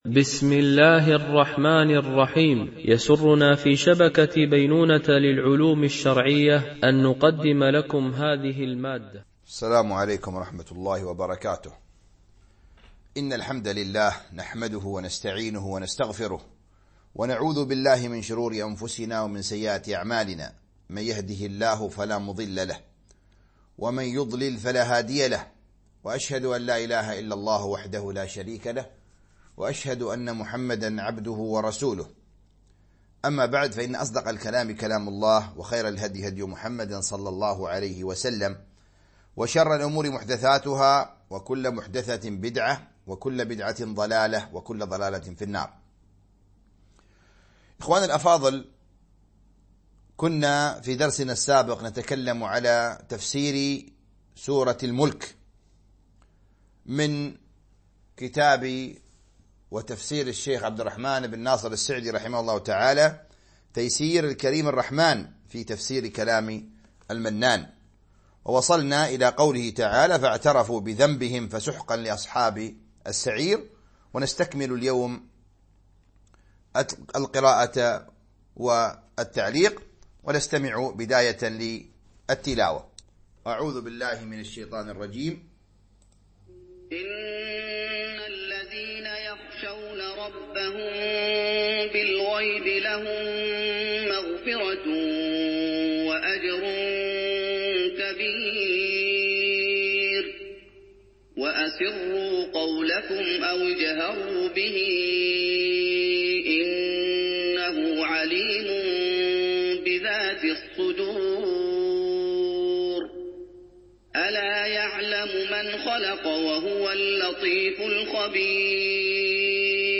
تفسير جزء تبارك - الدرس 02 ( تفسير سورة الملك - الجزء الثاني )